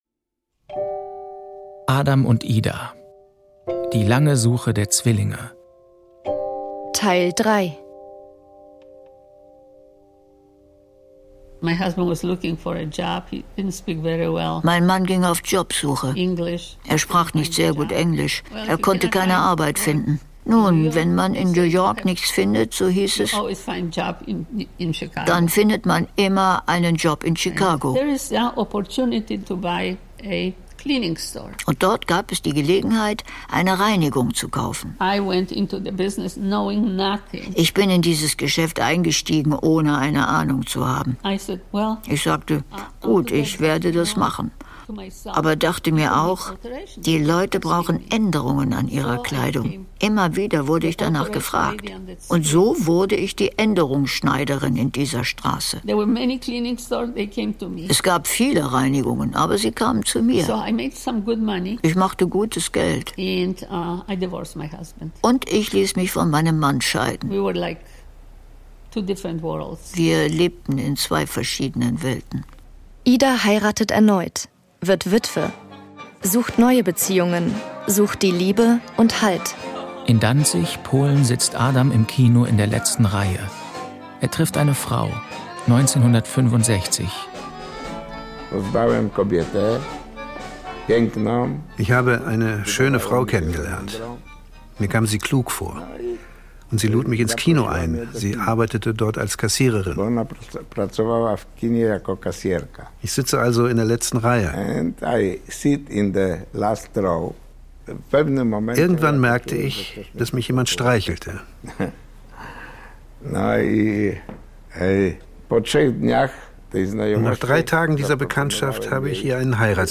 Die lange Suche der Zwillinge ist ein Feature